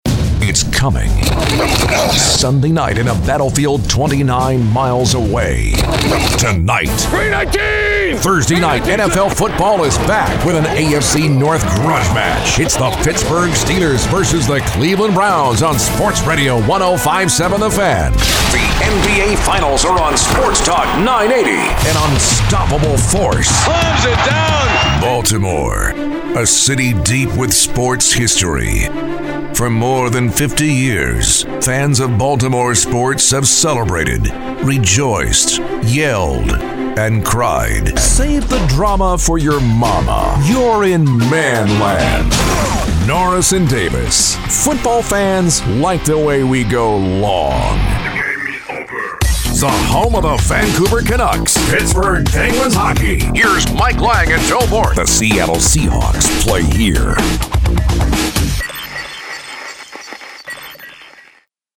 He is definitely not a “one read” person.
Imaging Voice Demos: